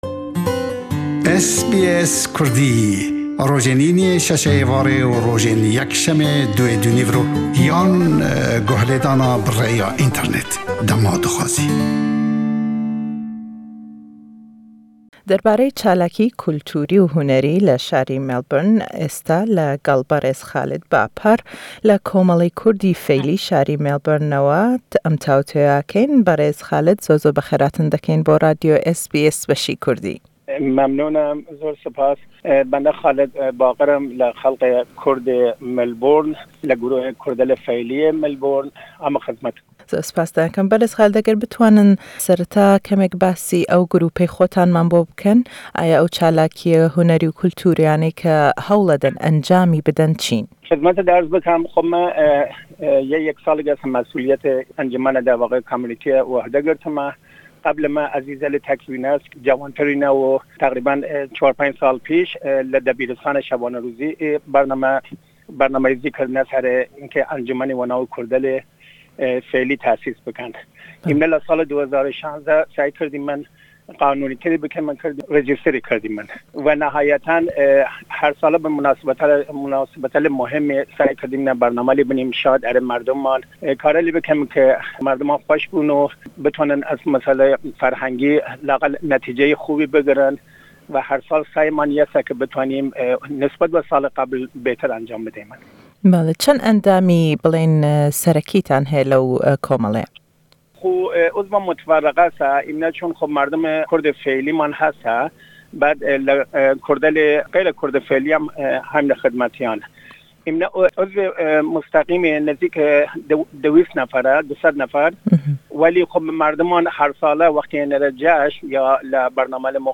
le em witûwêje